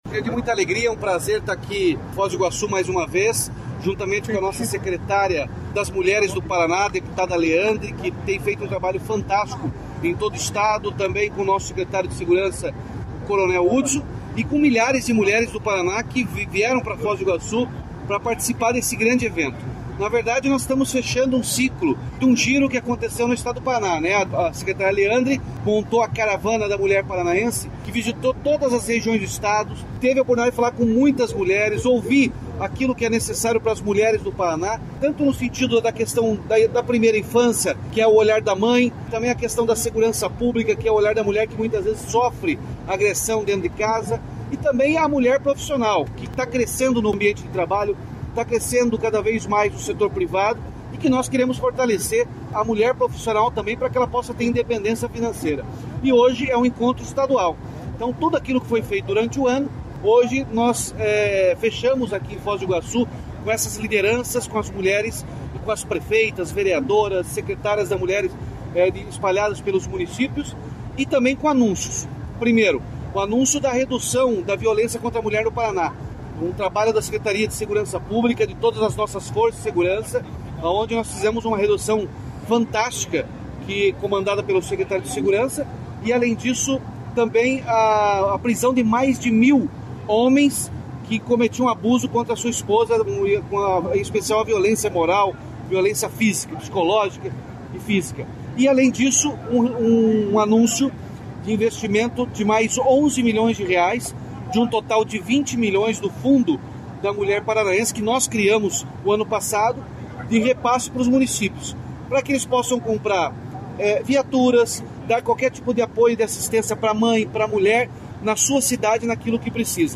Sonora do governador Ratinho Junior sobre o encerramento da temporada da Caravana Paraná Unido Pelas Mulheres | Governo do Estado do Paraná